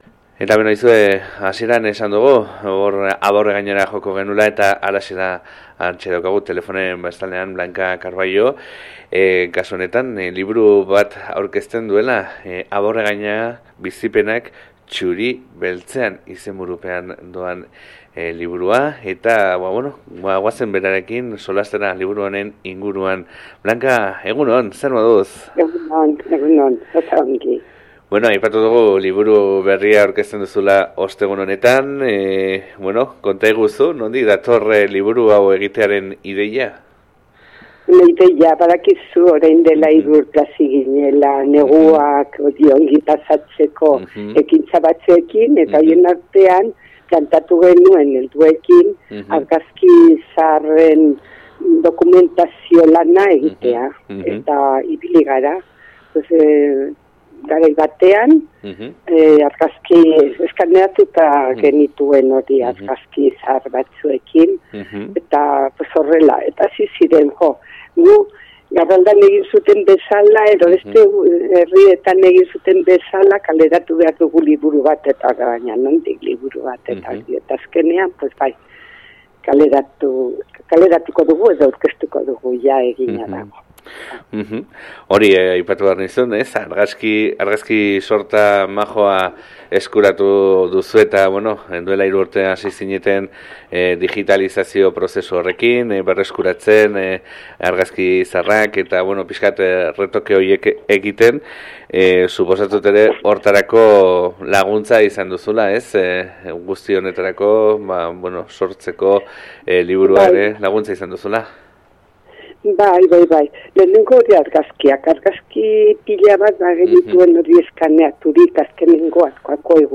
Kartela: Klikatu hemen elkarrizketa jaisteko Partekatu Click to share on Facebook (Opens in new window) Click to share on Twitter (Opens in new window) Click to email a link to a friend (Opens in new window) Related